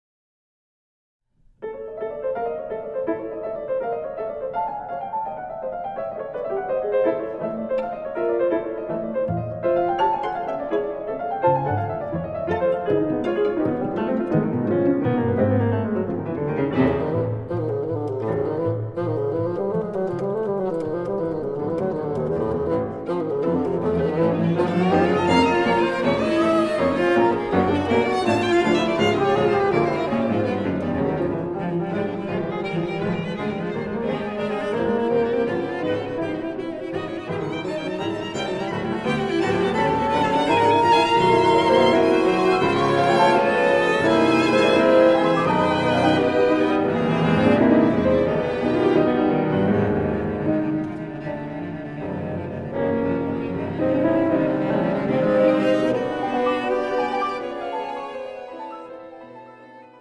Nocturne (3:21)
Oboe